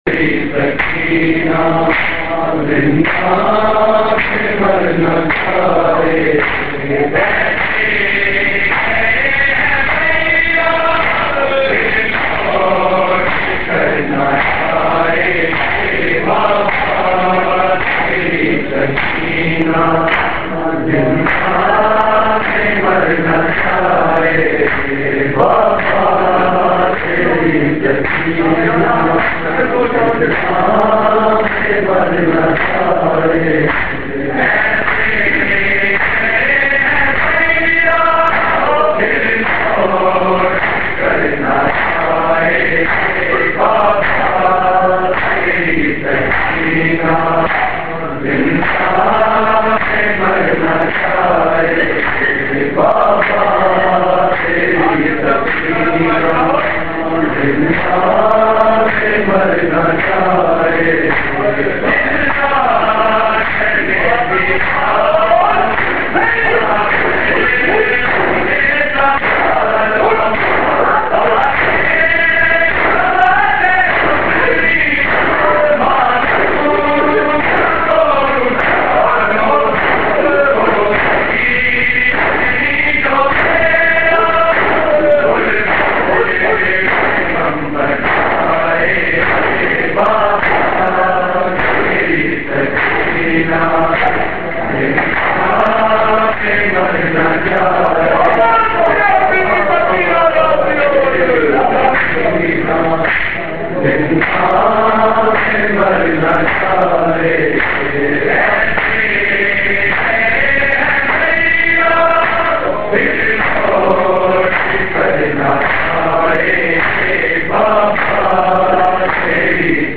Markazi Matmi Dasta, Rawalpindi
Recording Type: Live
Location: Rawalpindi